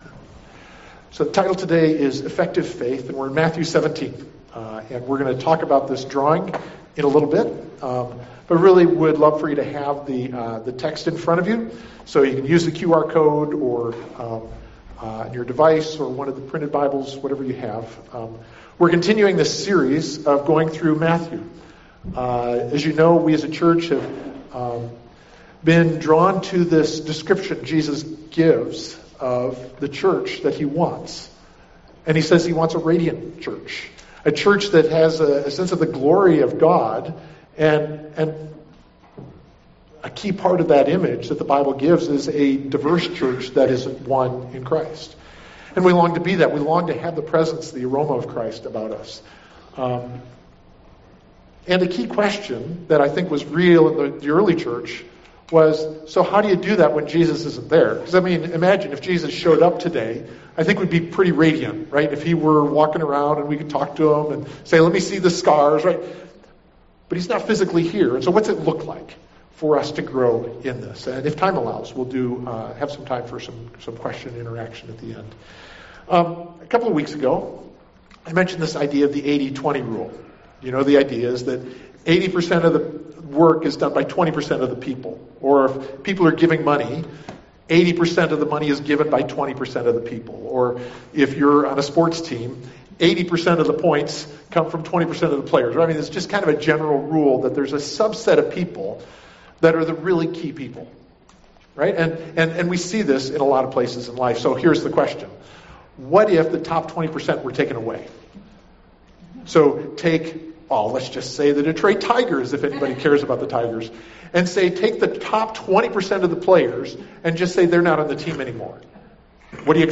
Effective Faith [Matthew 17:14–21] October 5, 2025 Matthew Watch Listen Save Sermon Handout Sermon Slides Matthew 17:14–21 Audio (MP3) 11 MB Previous What Kind of Messiah?